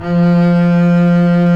Index of /90_sSampleCDs/Roland - String Master Series/STR_Cb Bowed/STR_Cb2 f vb